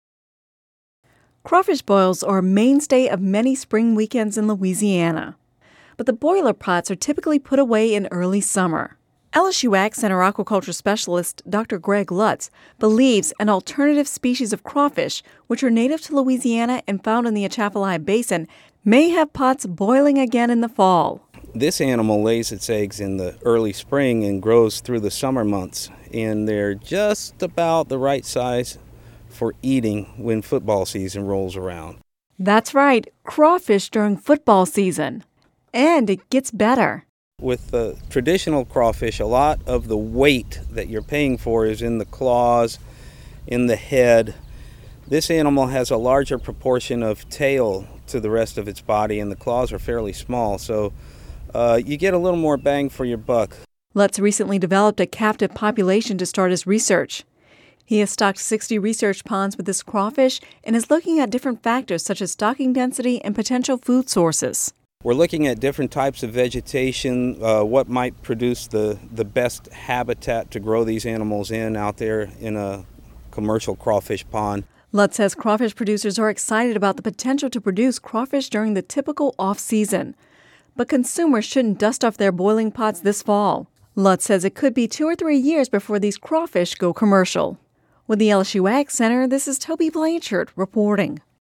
(Radio News 07/05/10) Crawfish boils are a mainstay of many spring weekends in Louisiana, but the boiler pots typically are put away in early summer.